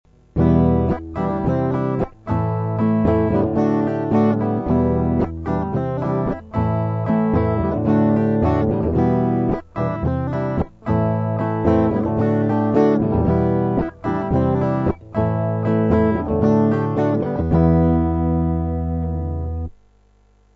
Проигрыш (Em - C - Am - D):